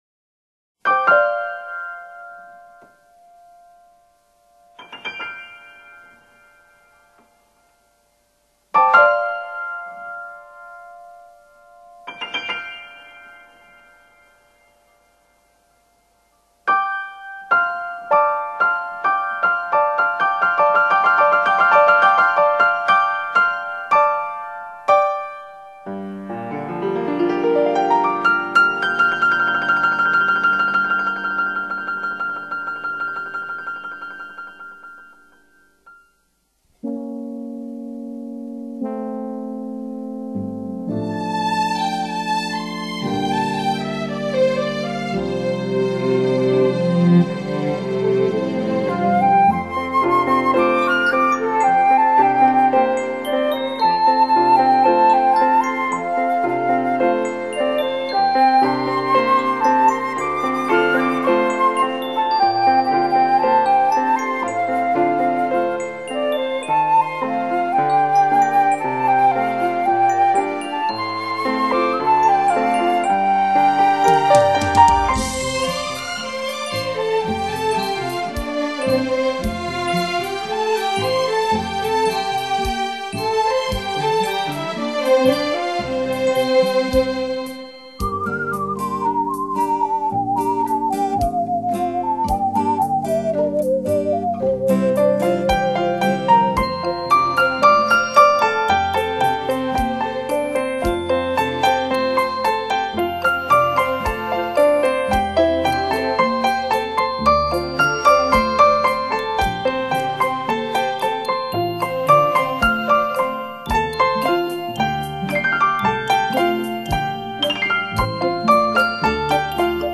HiFi钢琴示范录音